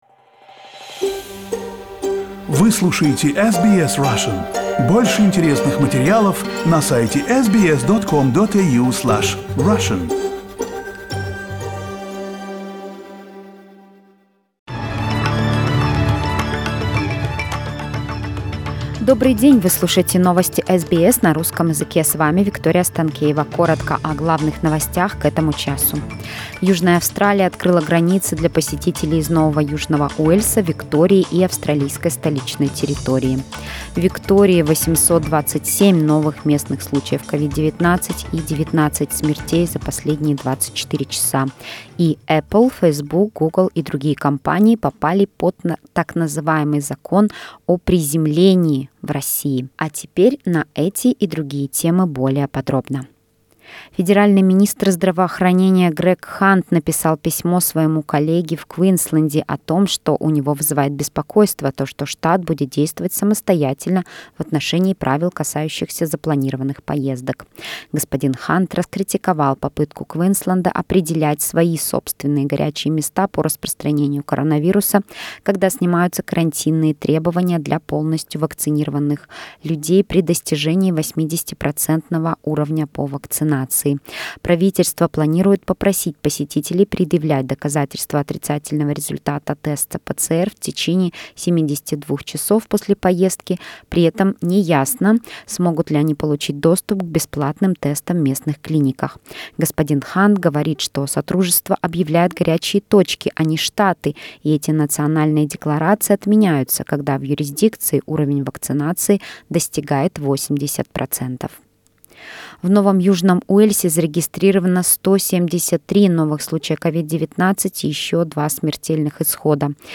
SBS news in Russian - 23.11